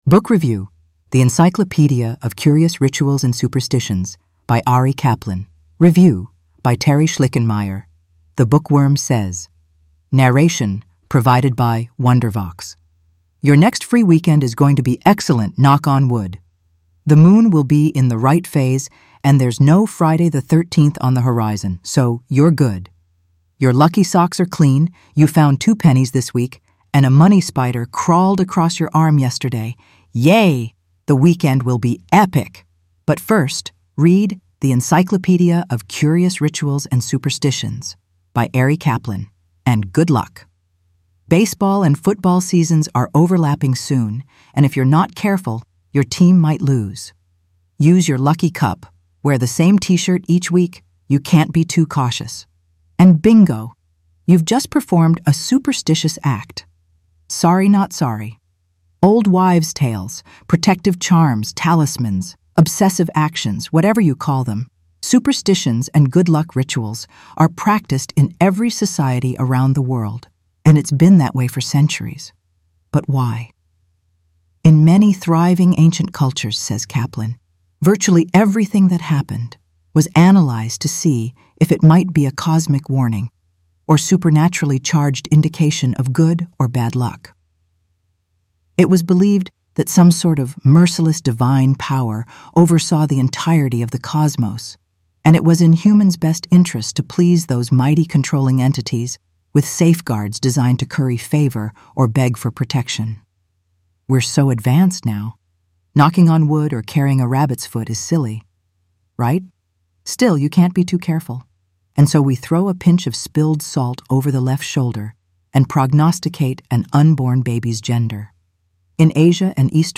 Narration provided by Wondervox